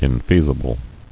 [in·fea·si·ble]